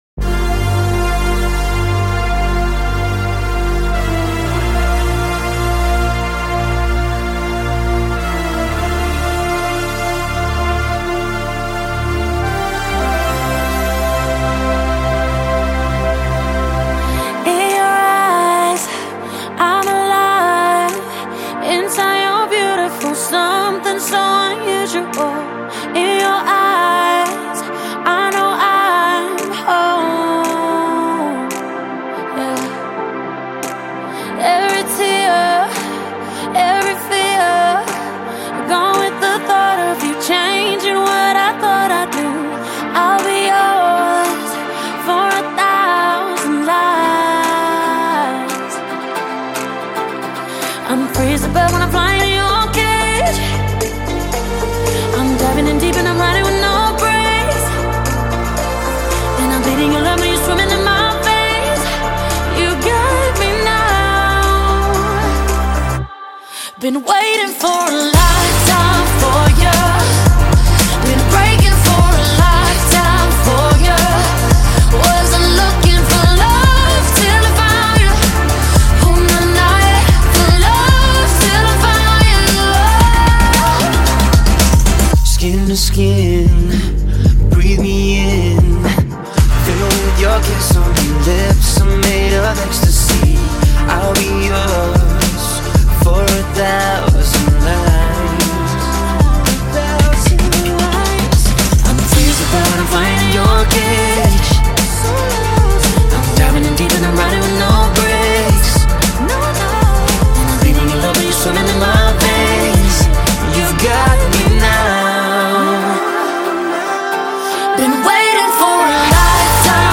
Жанр: Саундтреки / Саундтреки